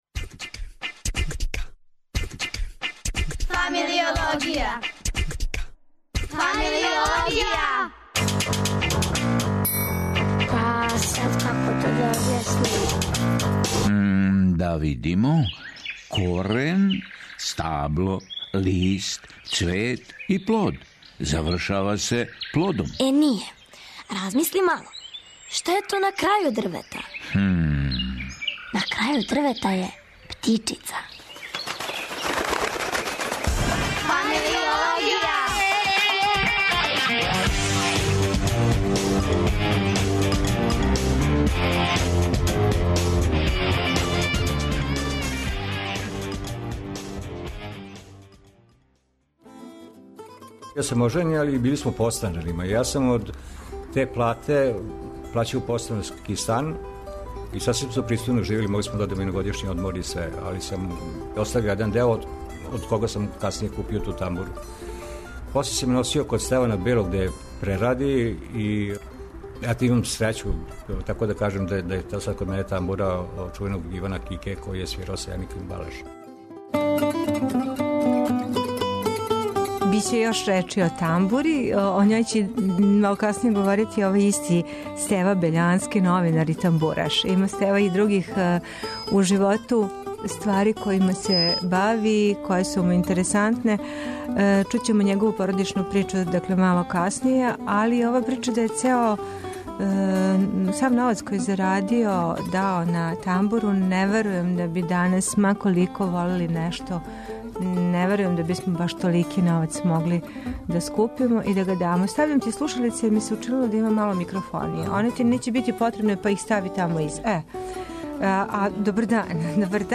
Породичну причу казује
гост у студију је